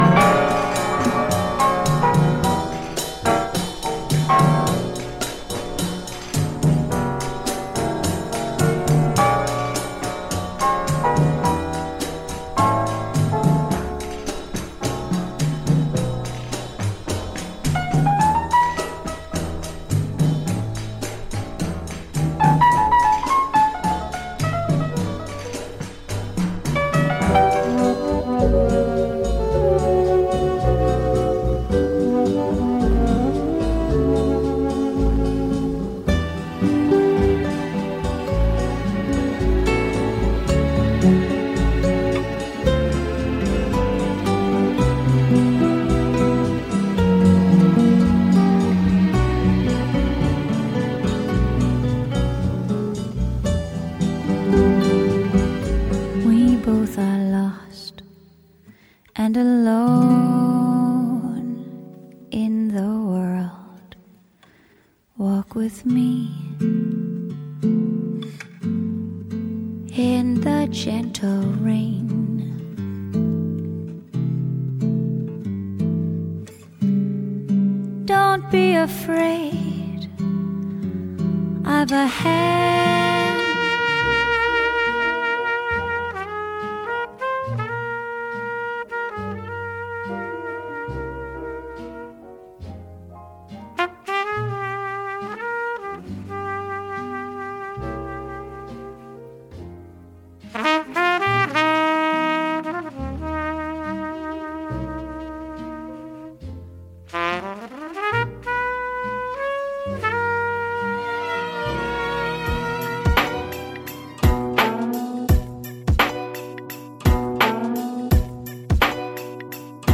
Hotel Lobby Mellow Vibe
Blend of Bossa Nova, Lounge and Jazz Music